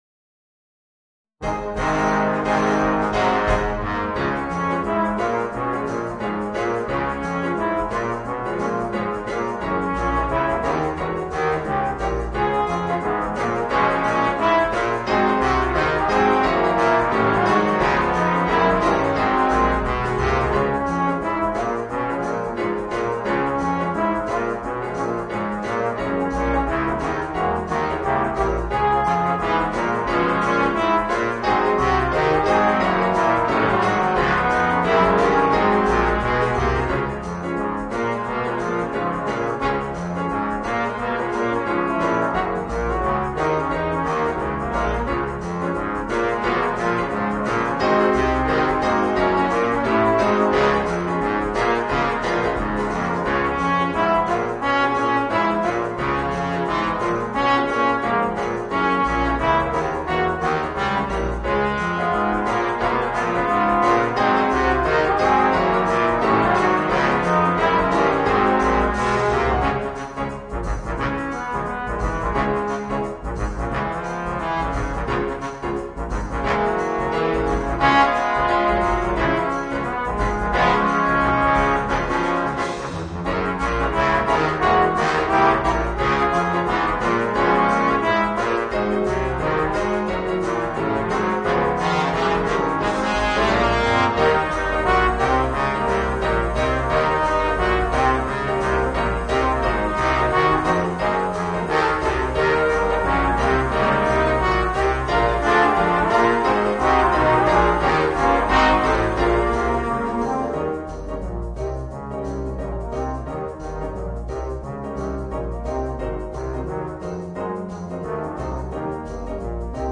Voicing: 4 Trombones and Piano